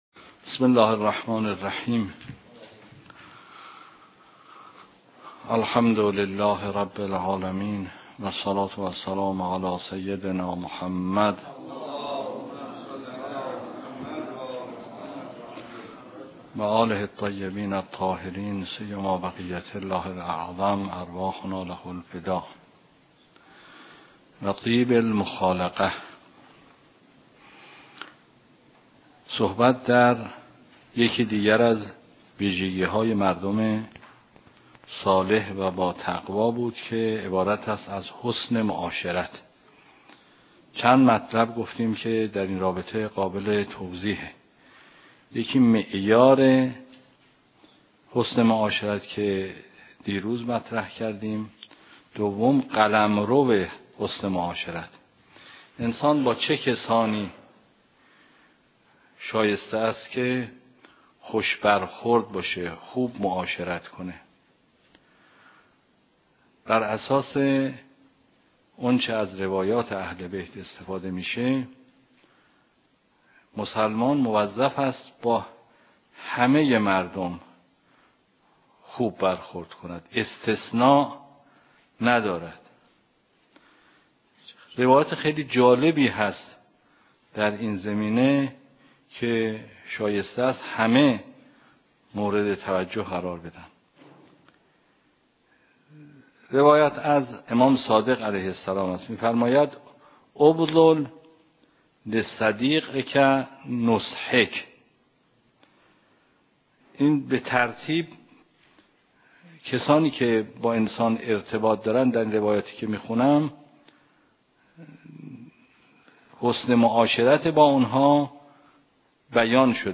درس خارج فقه مبحث حج